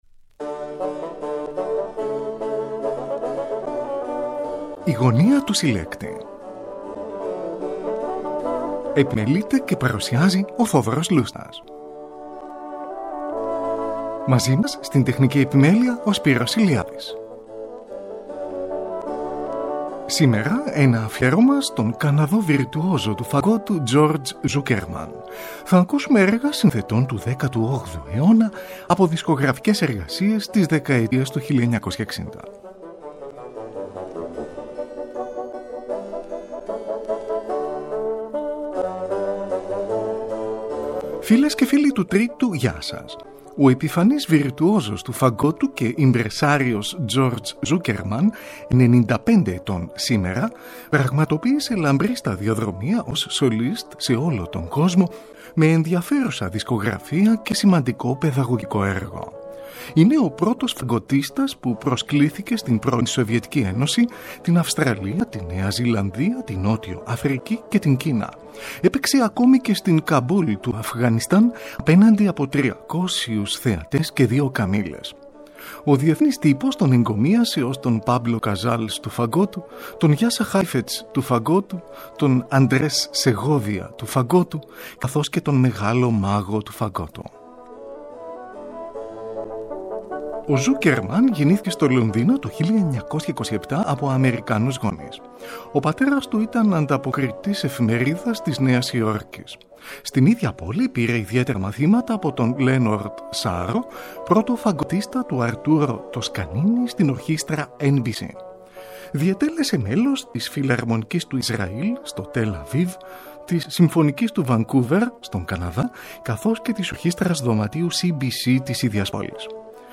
ΦΑΓΚΟΤΙΣΤΑ
από δισκογραφικές εργασίες της δεκαετίας του 1960